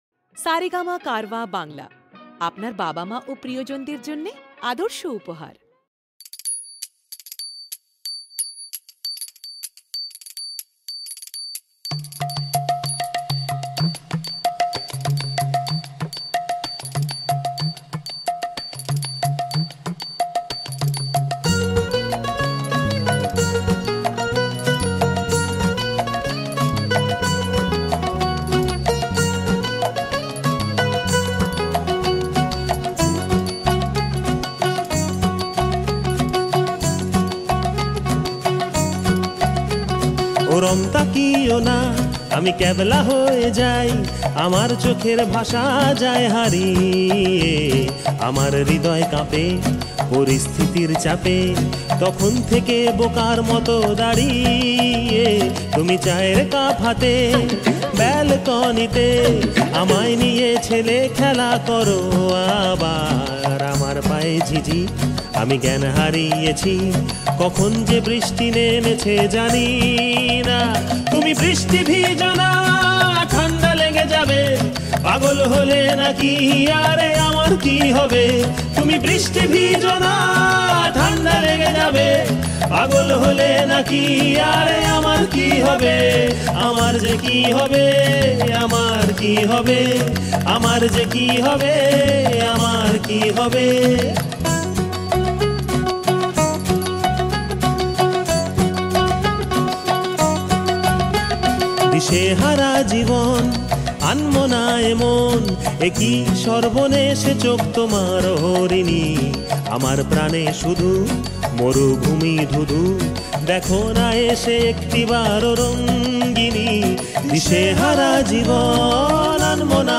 Bengali